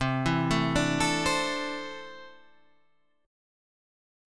C6add9 Chord